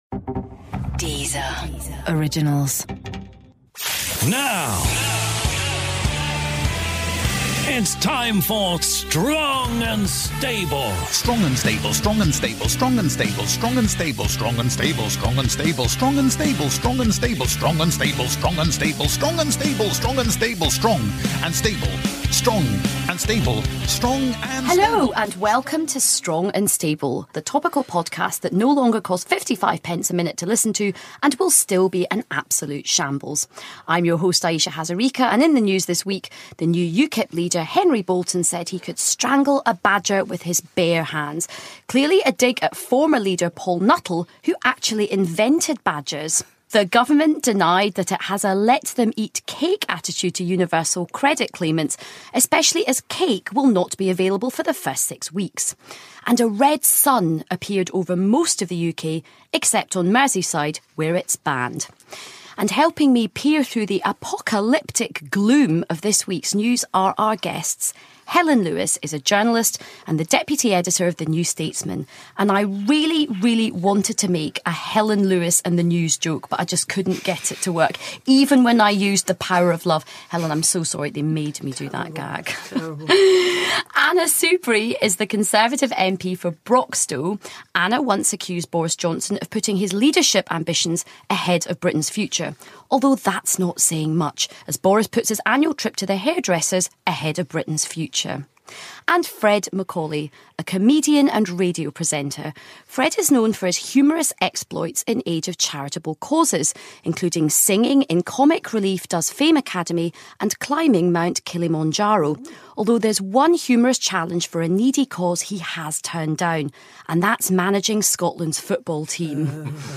This week on Strong & Stable our host Ayesha Hazarika is joined in the studio by guests Helen Lewis, Anna Soubry MP and Fred MacAulay. On the agenda are the threat of 'no deal' Brexit, Trump's latest gaffs and how to get more women into politics. We also hear from roving reporter Jonathan Pie, and Jan Ravens channeling the Prime Minister.